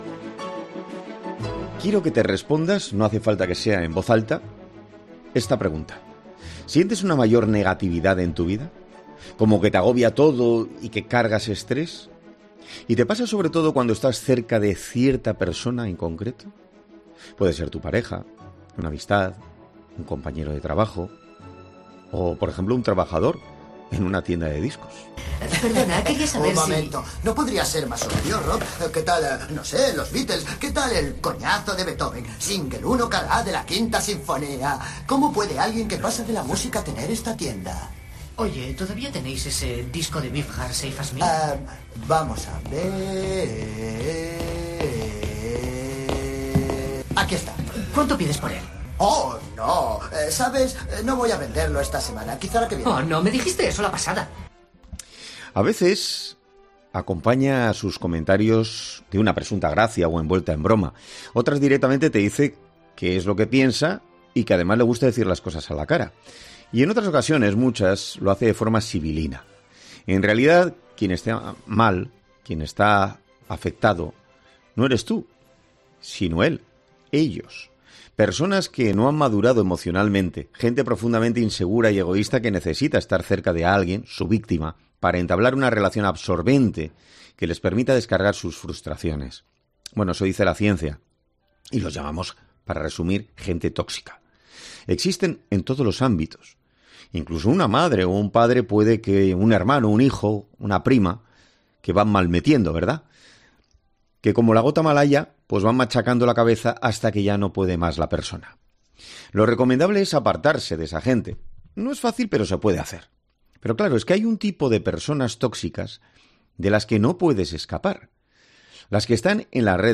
psiquiatra y psicólogo, explica cómo es una persona tóxica